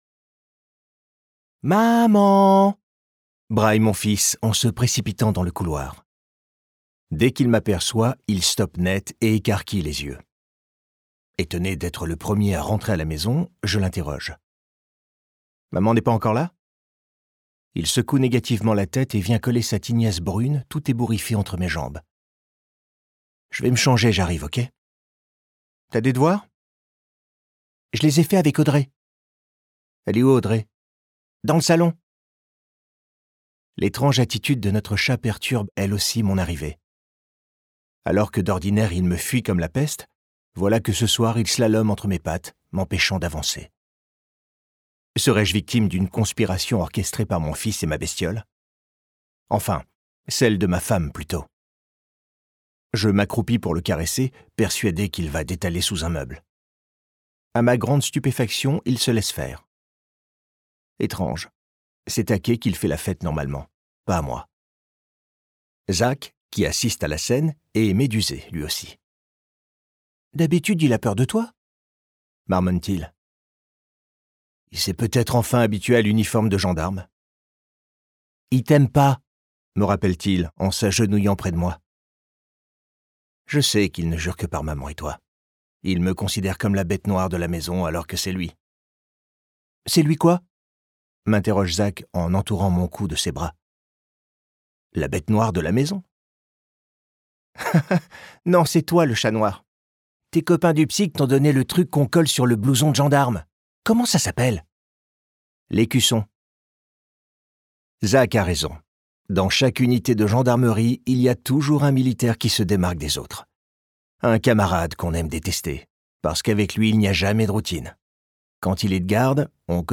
*IH ou Interprétation Humaine signifie que des comédiennes et comédiens ont travaillé à l'enregistrement de ce livre audio, et qu'aucune voix n'a été enregistrée avec l'intelligence artificielle.